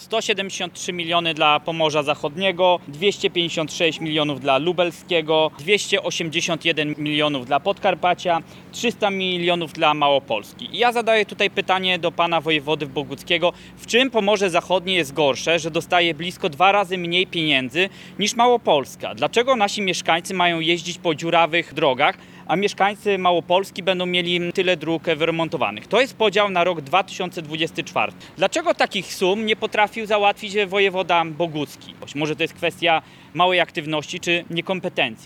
Dziś przed Urzędem Wojewódzkim zorganizowali konferencję prasową dotyczącą Rządowego Funduszu Rozwoju Dróg i pieniędzy z tego programu dla naszego regionu.